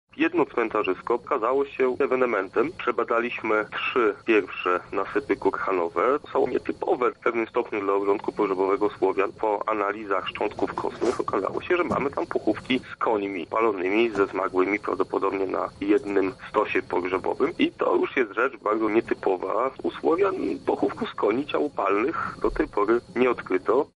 archeolog